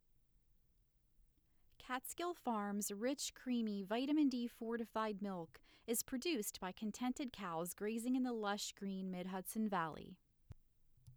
I’m attaching a new sample untouched, can you tell me how I’m sounding now?
Your voice has some powerful “Essing.” All your SS sounds are boosted.